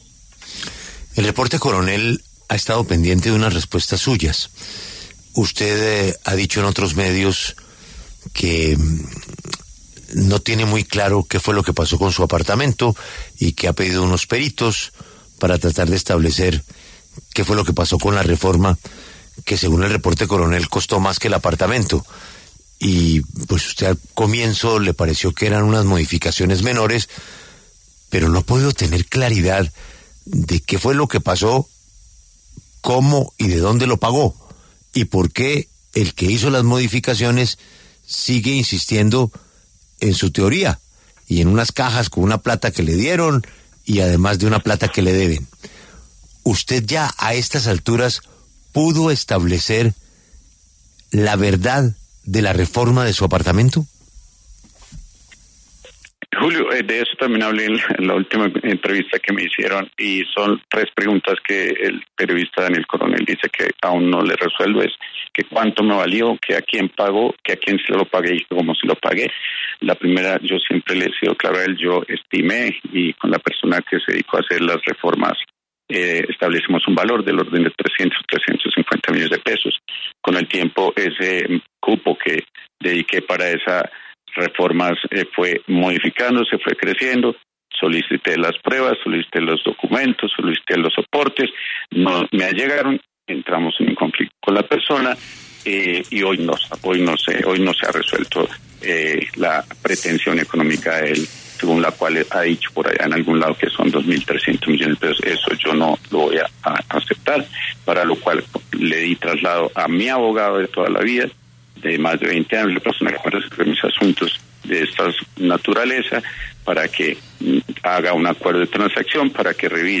En conversación con La W, Ricardo Roa, presidente de Ecopetrol, respondió las preguntas que se hicieron en El Reporte Coronell sobre el costo de la remodelación de su apartamento.